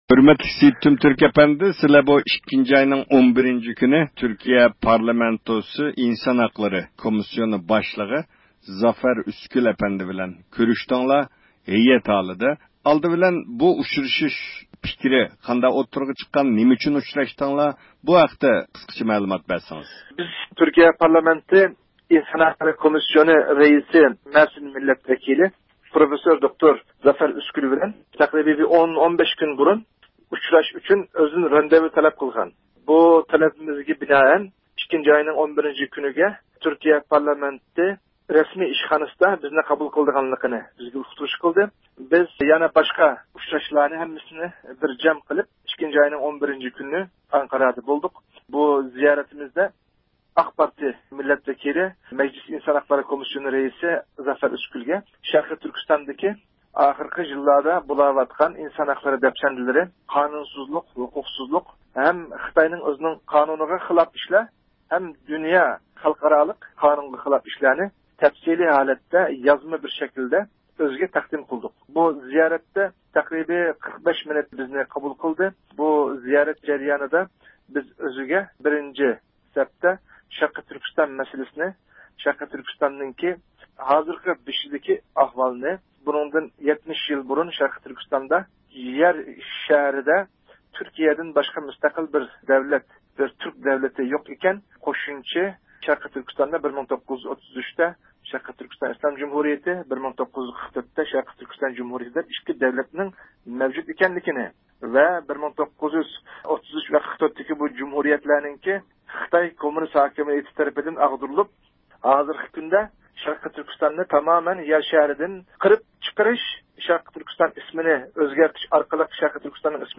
سۆھبەت ئېلىپ باردۇق